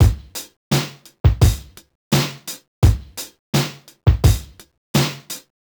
Index of /musicradar/sampled-funk-soul-samples/85bpm/Beats
SSF_DrumsProc1_85-01.wav